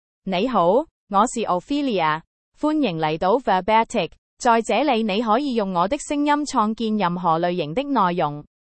Ophelia — Female Chinese (Cantonese, Hong Kong) AI Voice | TTS, Voice Cloning & Video | Verbatik AI
Ophelia is a female AI voice for Chinese (Cantonese, Hong Kong).
Voice sample
Female
Ophelia delivers clear pronunciation with authentic Cantonese, Hong Kong Chinese intonation, making your content sound professionally produced.